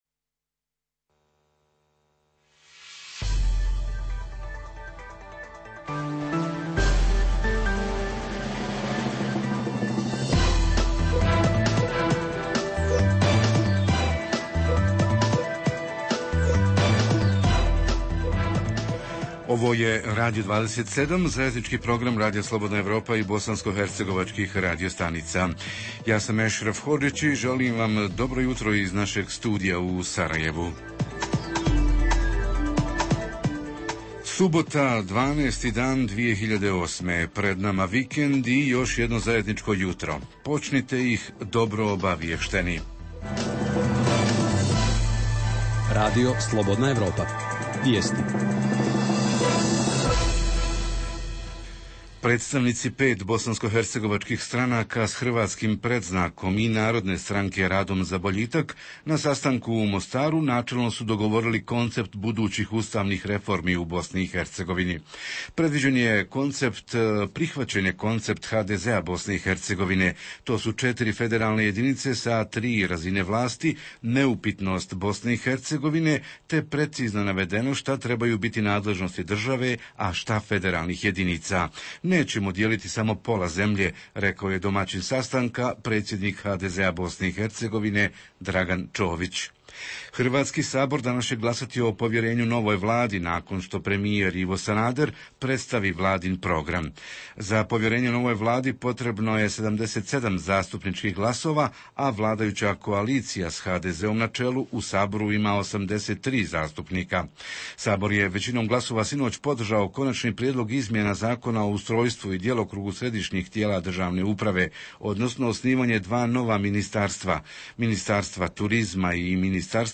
Jutarnji program za BiH koji se emituje uživo. U emisiji govorimo o turističkoj “žetvi” prošle i kako unaprijediti turističku ponudu ove godine?